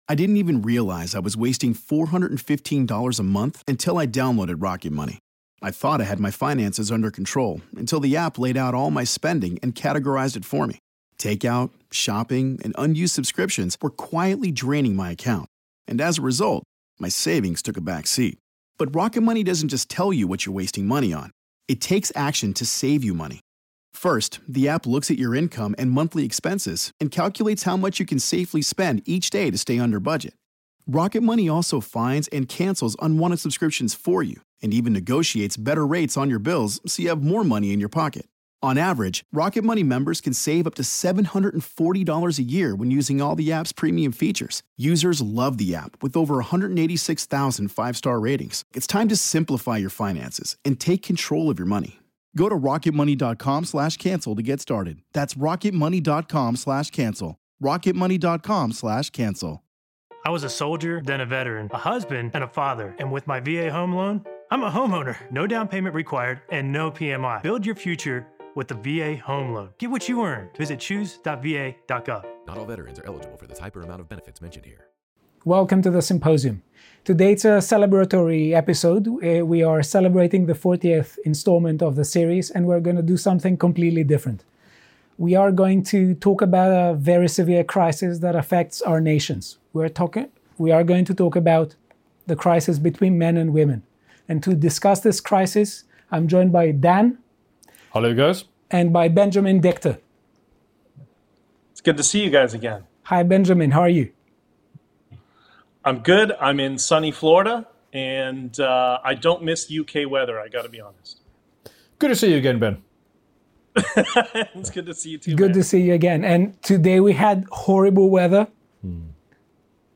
A philosopher, an economist, and the leader of Canada’s Freedom Convoy walk into a studio… and discuss how to bring men and women together.